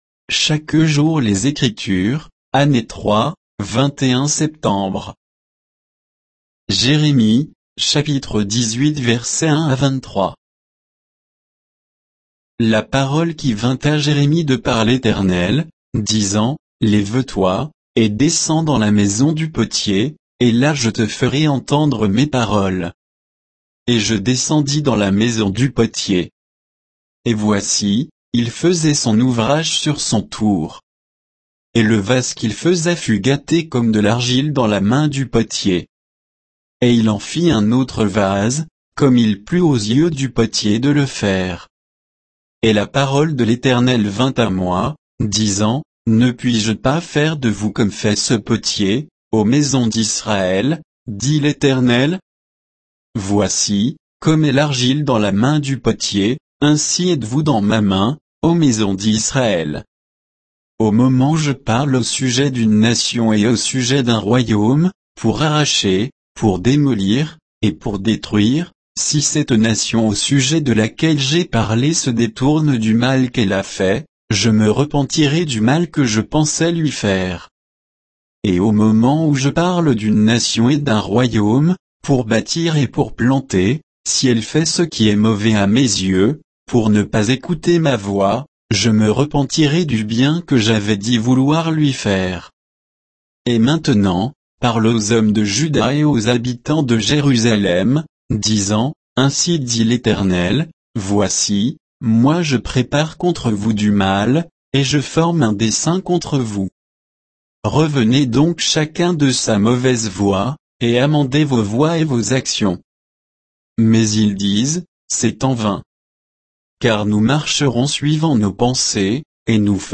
Méditation quoditienne de Chaque jour les Écritures sur Jérémie 18